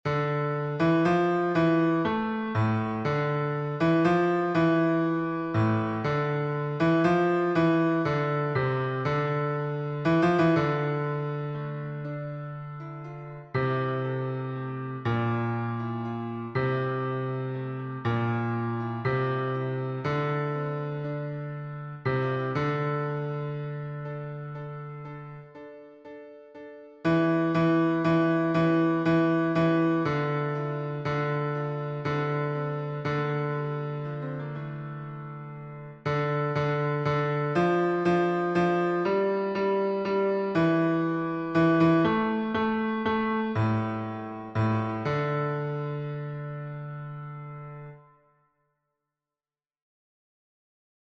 versions piano